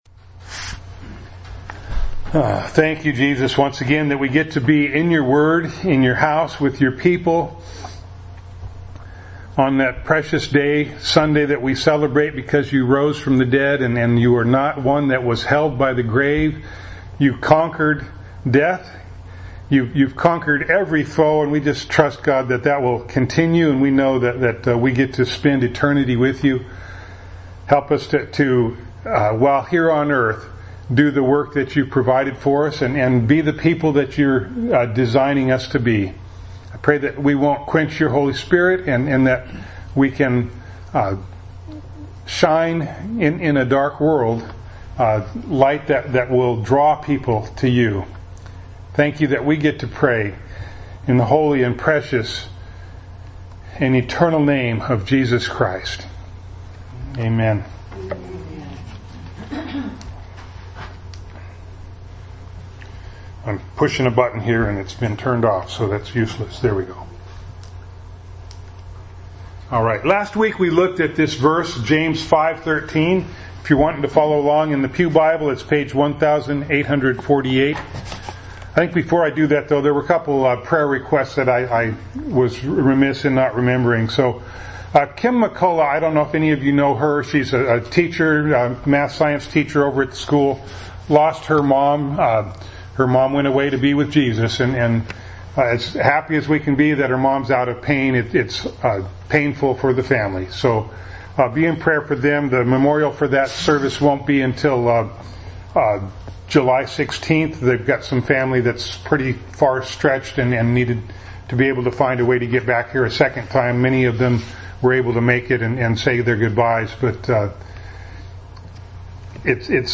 James 5:14-15 Service Type: Sunday Morning Bible Text